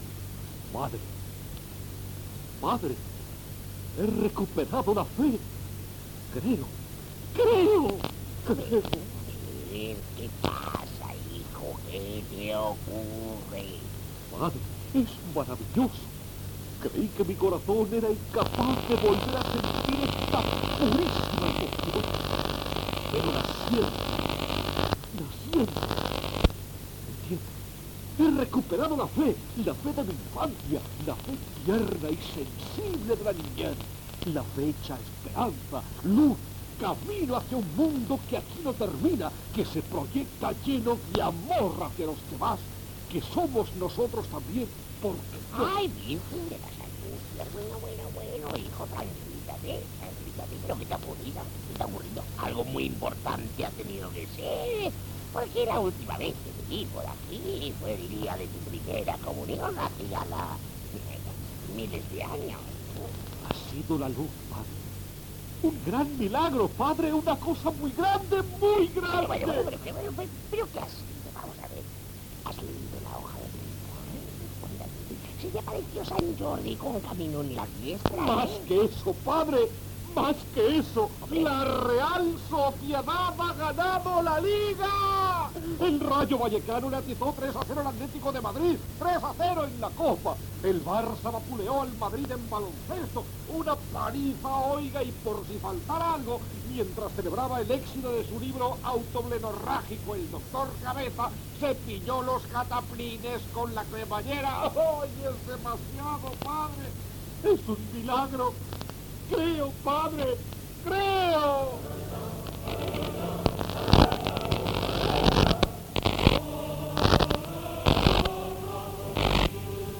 Una persona confessa que ha recuperat la fe, indicatiu del programa
Entreteniment
FM